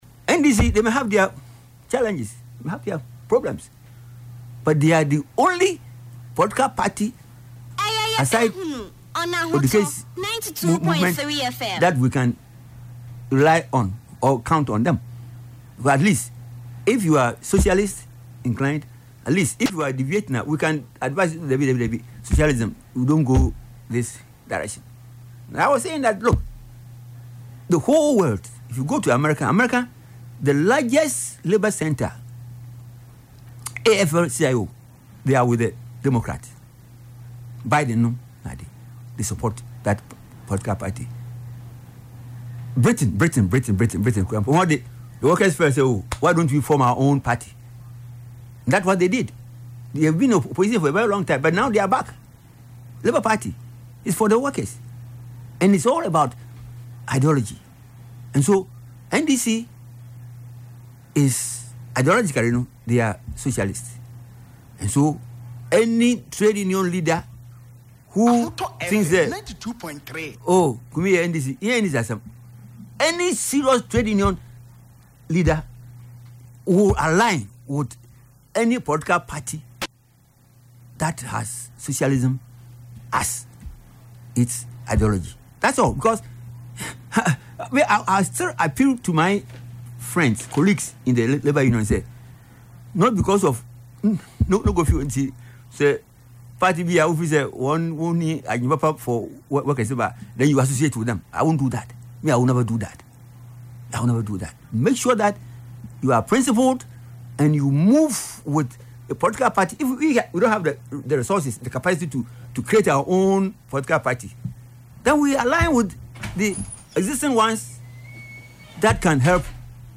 Speaking on Ahotor FM’s Yepe Ahunu programme on Saturday, February 14, he acknowledged that while the NDC may have its own challenges, it remains the most reliable political tradition for workers in Ghana due to its pro-labour inclination.